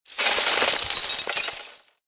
CHQ_VP_raining_gears.mp3